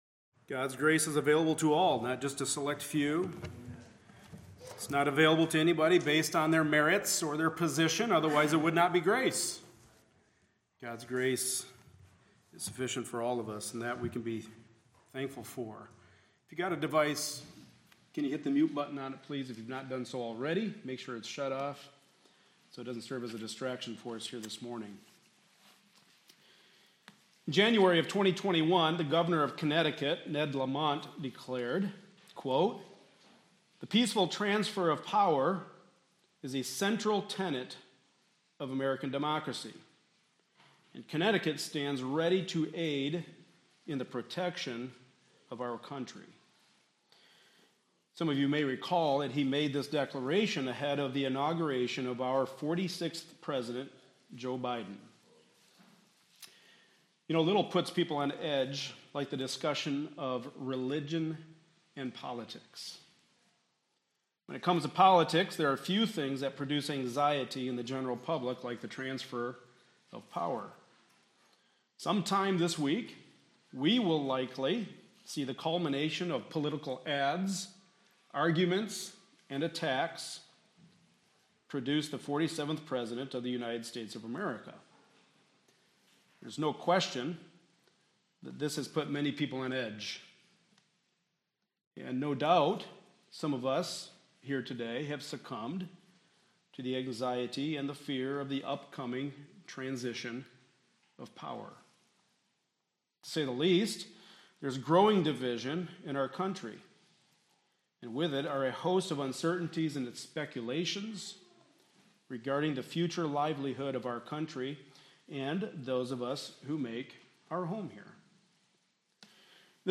Passage: 1 Kings 12 Service Type: Sunday Morning Service